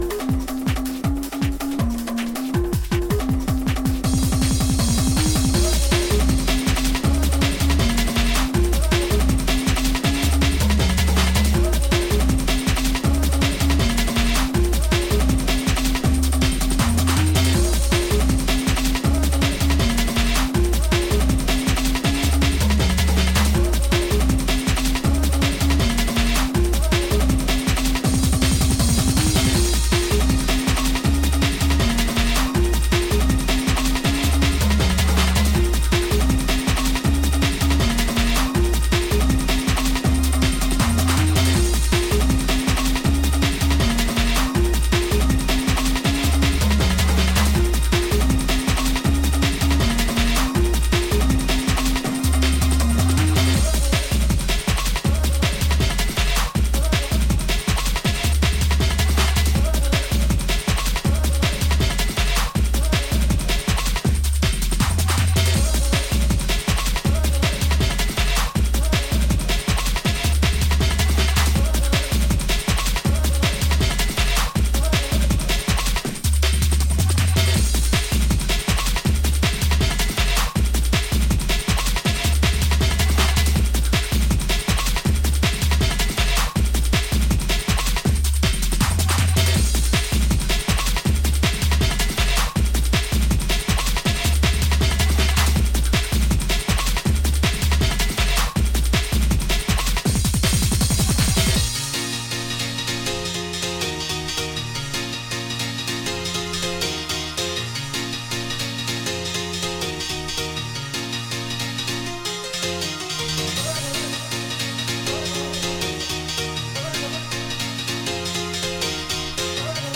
Drum & bass
Jungle